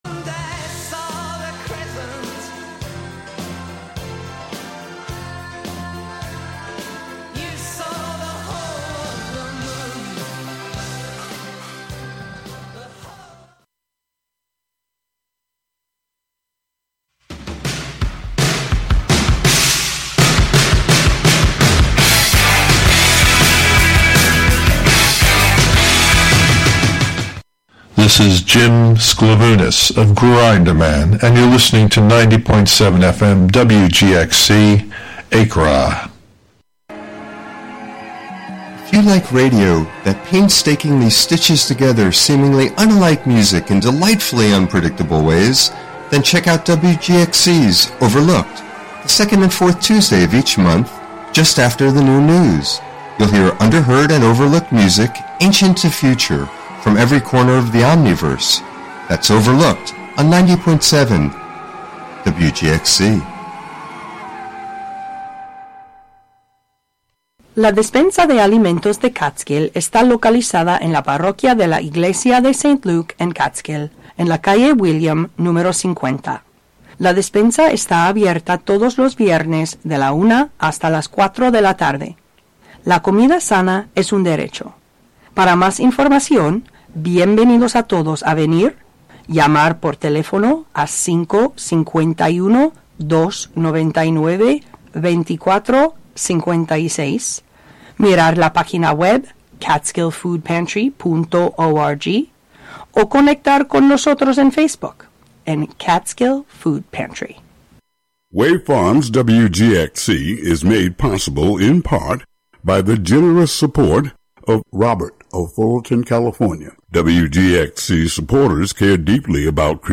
Each show, "The Radio Museum" broadcasts a collection of different voices from radio's past. True raconteurs who knew how to tell intimate, personal, engaging stories on the radio.
Plus some music from the era as well as a weekly recipe.